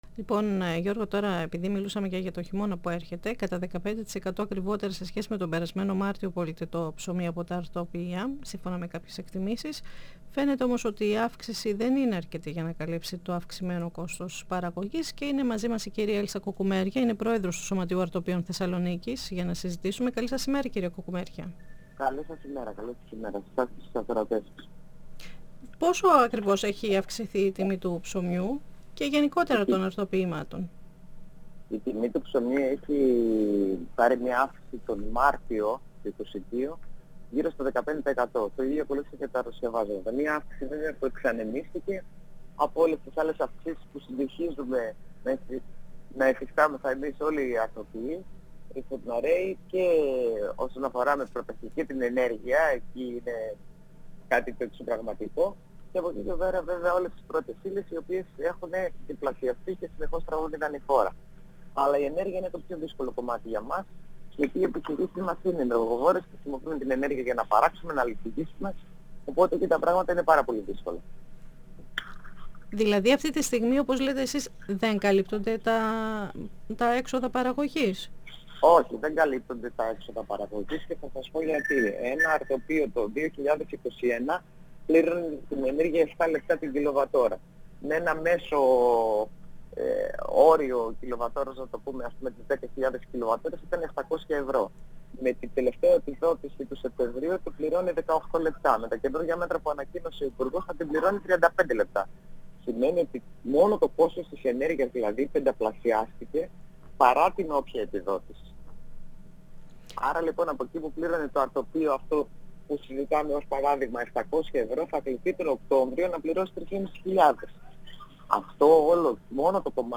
Σύμφωνα με την ίδια η μέση τιμή στο ψωμί έχει αυξηθεί 15% τον Μάρτιο, ενώ σε άλλα αρτοσκευάσματα καταγράφηκαν μεγαλύτερες αυξήσεις. 102FM Συνεντεύξεις ΕΡΤ3